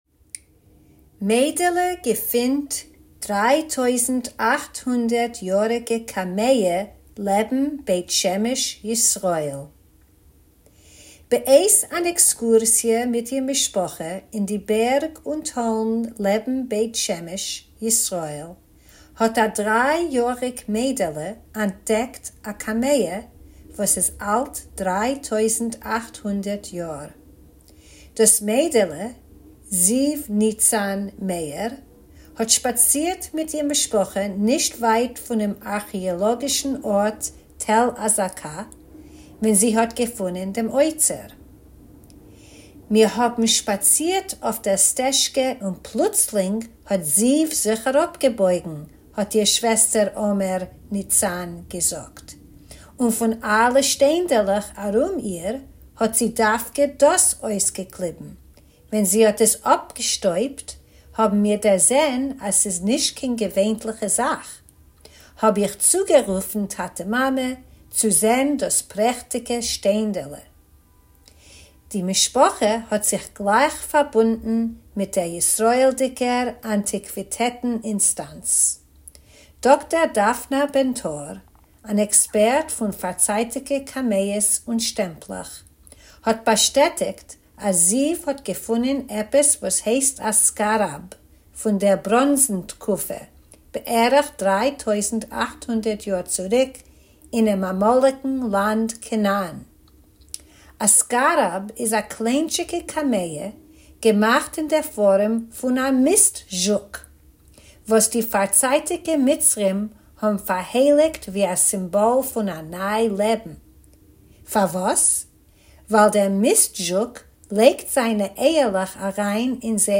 Tidbits is a bi-weekly feature of easy news briefs in Yiddish that you can listen to or read, or both!